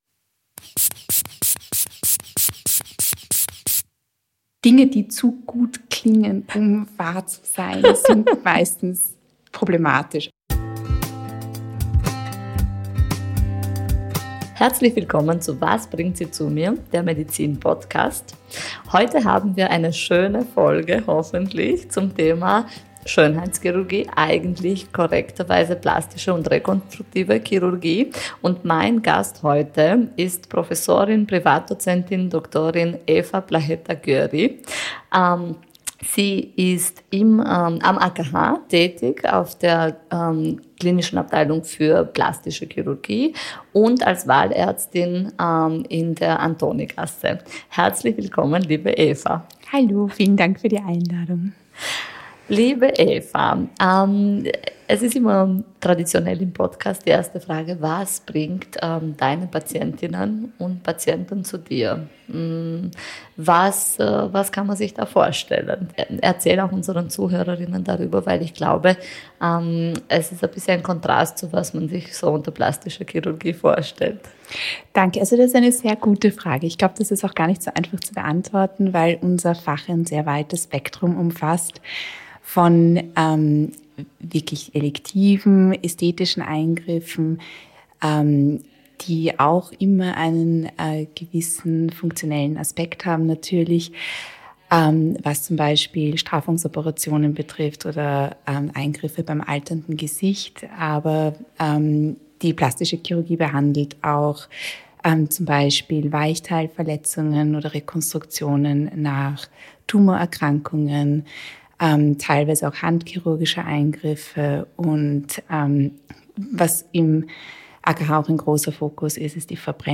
Ein Gespräch über Körperbilder, Patientenerwartungen, chirurgische Kunst – und echte Medizin.